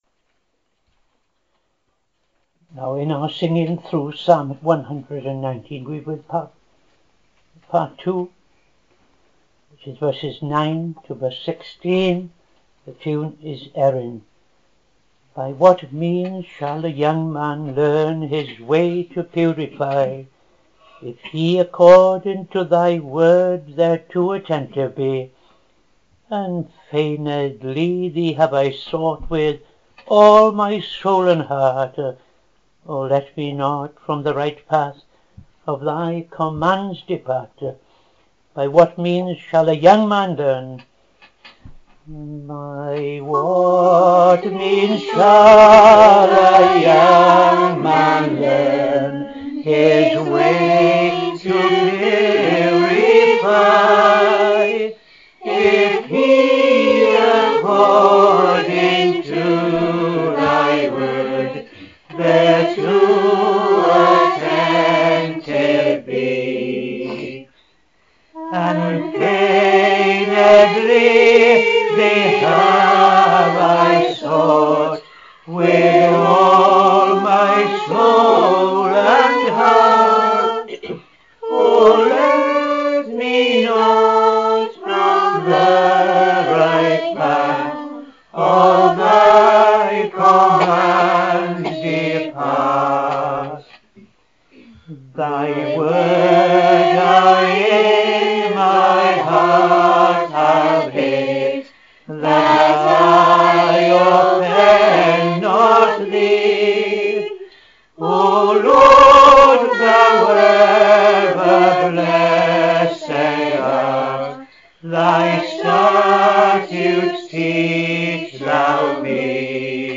5.00 pm Evening Service Opening Prayer and O.T. Reading I Chronicles 8:29-9:1
Psalm 147:14-20 ‘He in thy borders maketh peace’ Tune Denfield